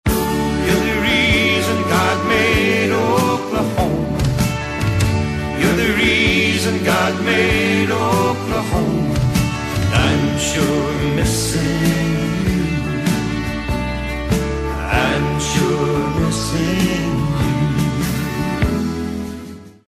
آهنگ زنگ عاشقانه غمگین